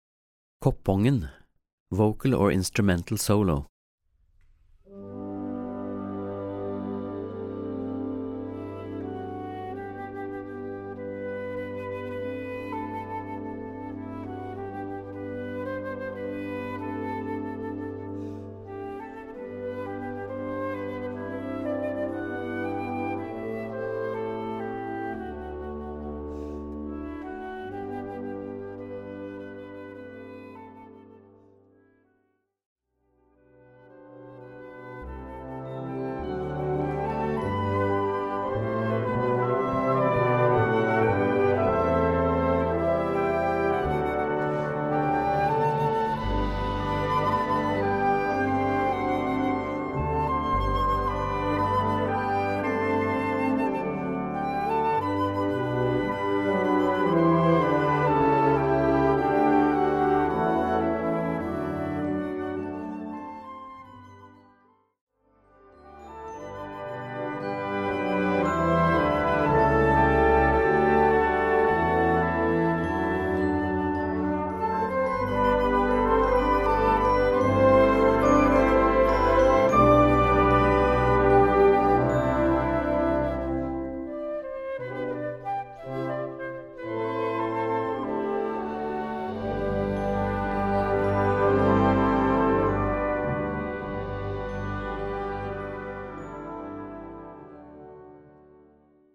Gattung: für Solo Gesang oder Flöte und Blasorchester
Besetzung: Blasorchester